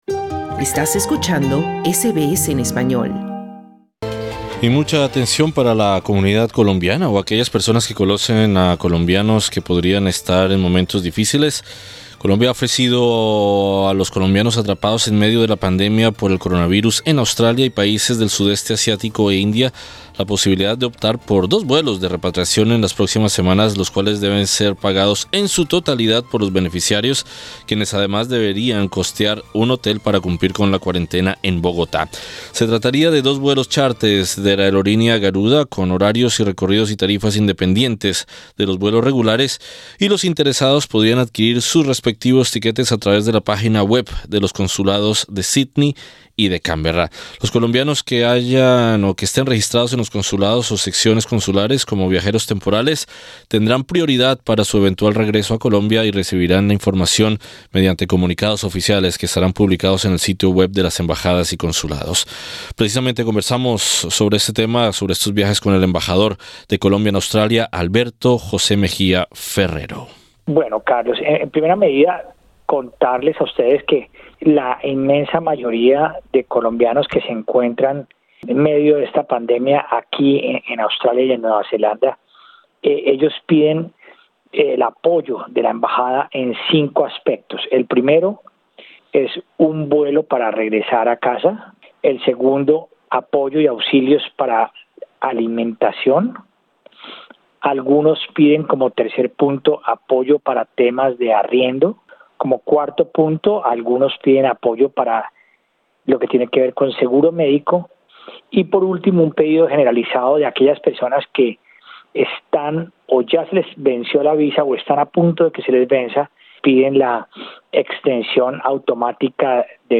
Sobre los vuelos y las ayudas que está ofreciendo Colombia a sus conciudadanos en Australia, conversamos con el embajador de Colombia en Australia Alberto José Mejía Ferrero.